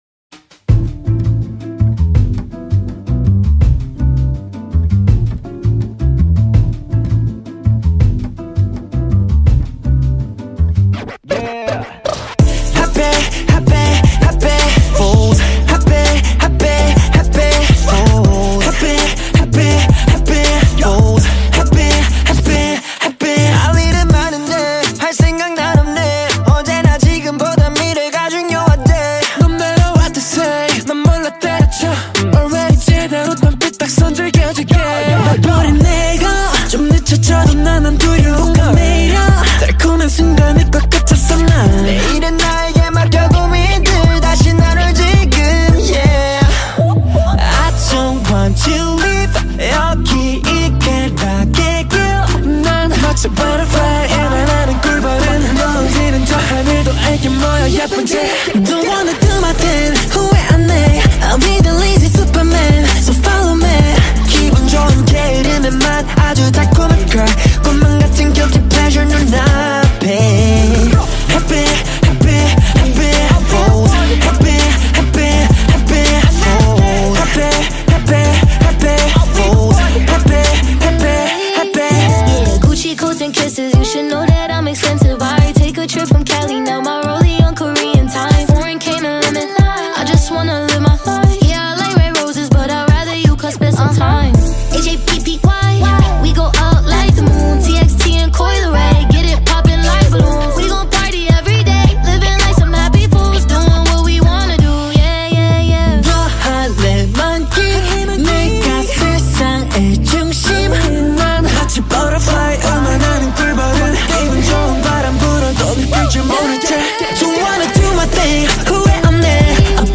Новая корейская музыка — K-Pop